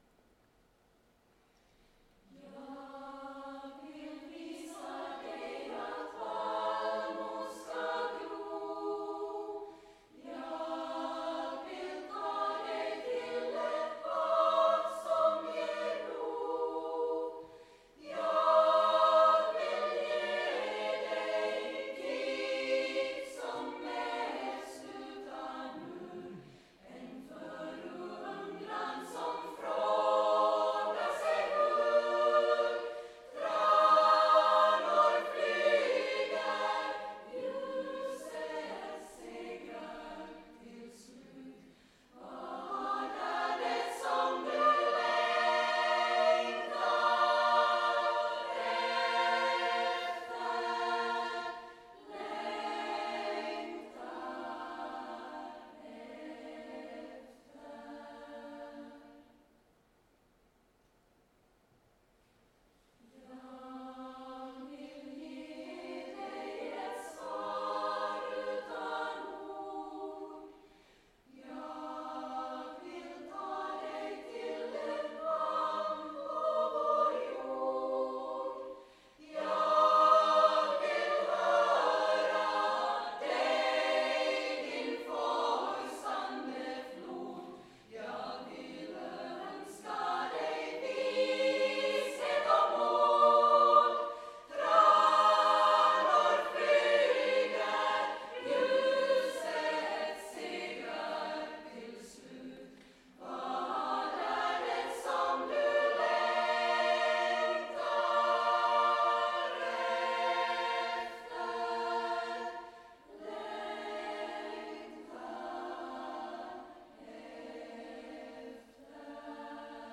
Sömntuta och tigerlilja – Damkören Madrigalen
Här kan du lyssna på några ljudinspelningar från vår 25års jubileumskonsert, Midvinterkonserten “Sömntuta och tigerlilja”